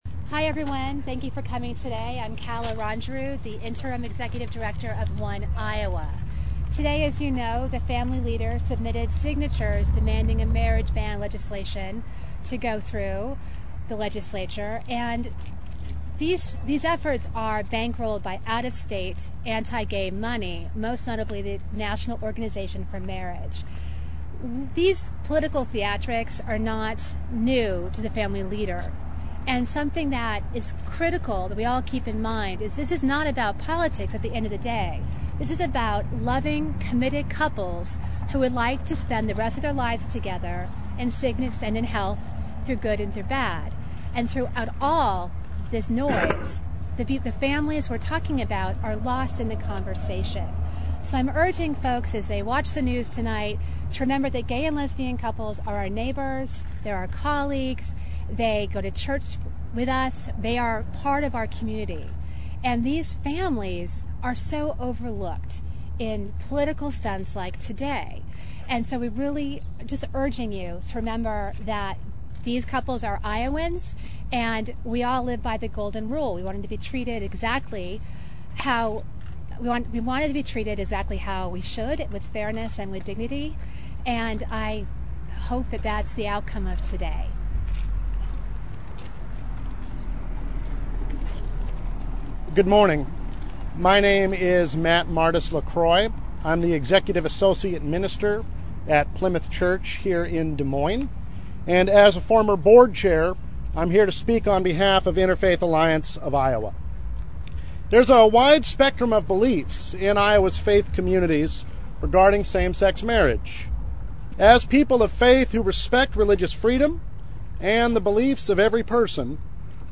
About an hour later, a group that supports same-sex marriage held a news conference outdoors, on the steps of the statehouse. Senator Matt McCoy, a Democrat from Des Moines who is the only openly gay member of the legislature, blasted Vander Plaats and dismissed the rally as a “stunt” designed to attract media attention.